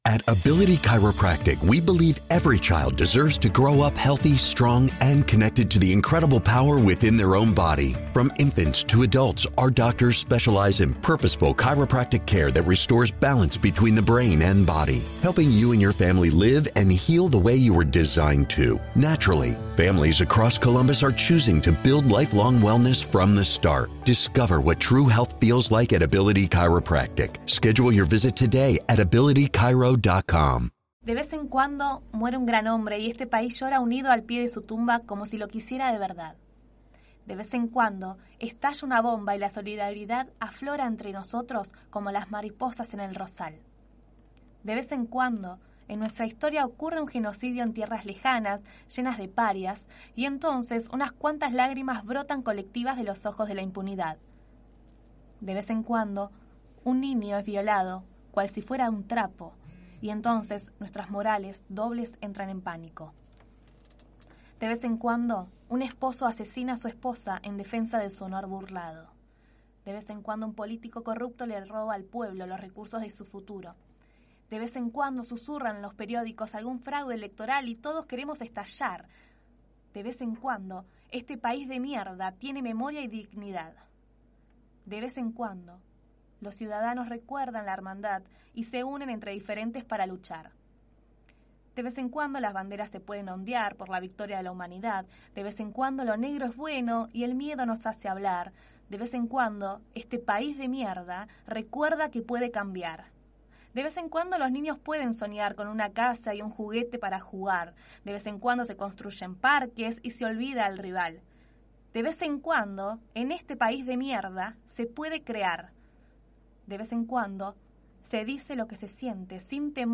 Poesía